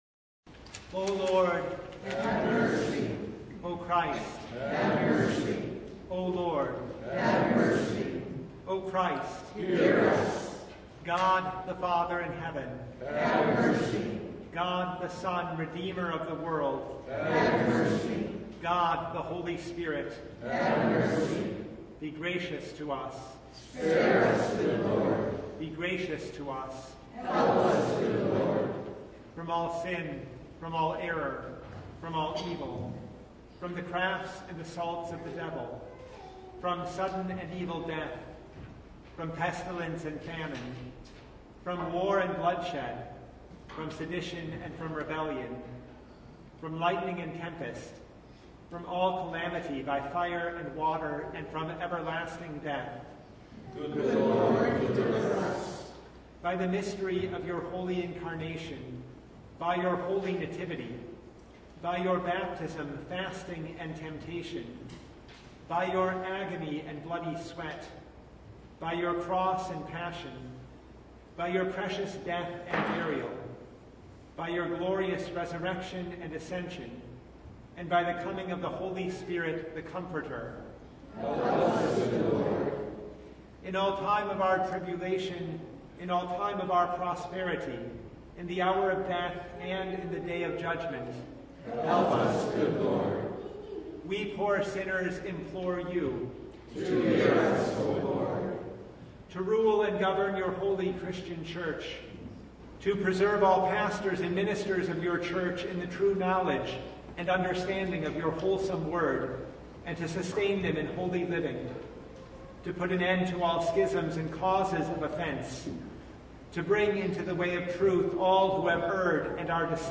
John 10:31-42 Service Type: Lent Midweek Noon Topics: Full Service « The Two Realms